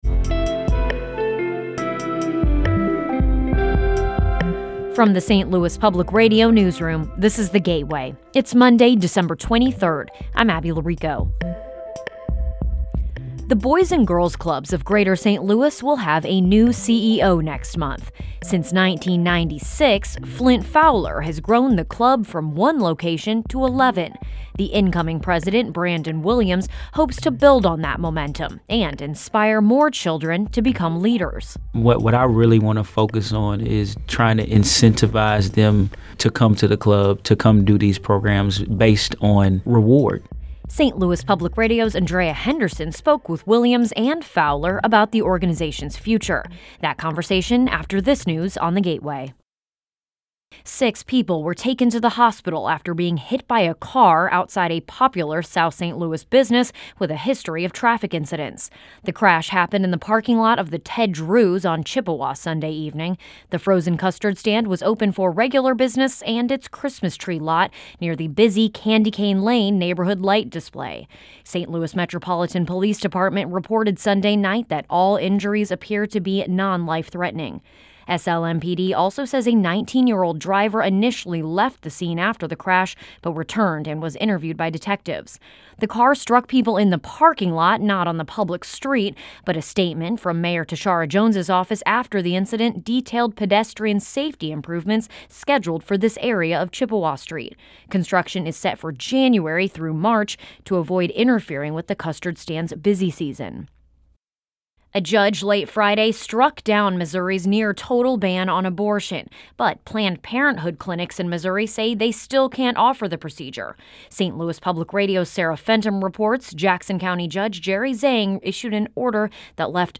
Essential news for the St. Louis region. Every weekday, in about 8 to 10 minutes, you can learn about the top stories of the day, while also hearing longer stories that bring context and humanity to the issues and ideas that affect life in the region.